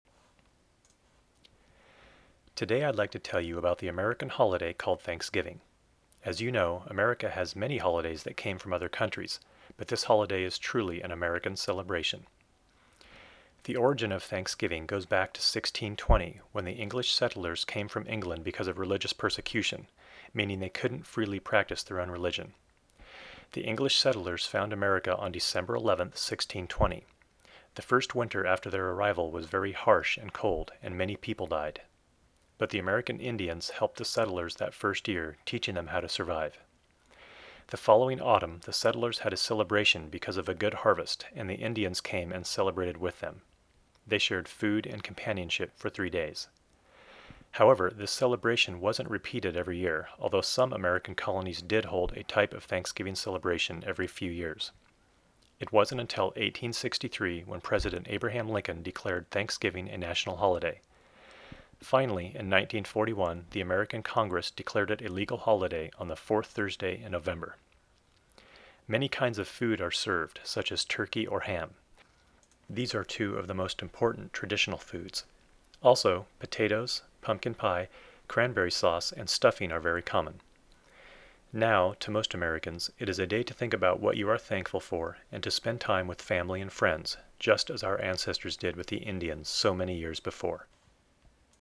昨年と同様に、感謝祭の由来についてネイティブに